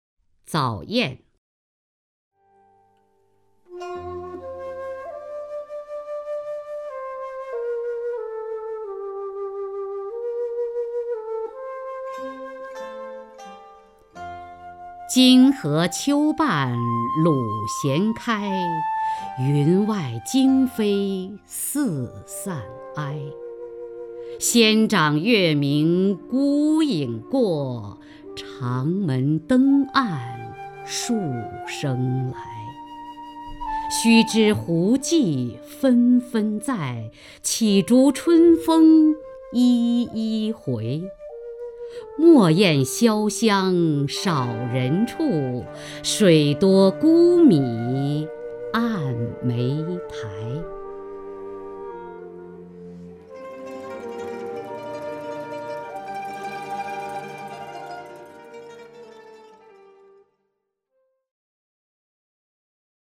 雅坤朗诵：《早雁》(（唐）杜牧) （唐）杜牧 名家朗诵欣赏雅坤 语文PLUS